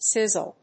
音節siz・zle 発音記号・読み方
/sízl(米国英語)/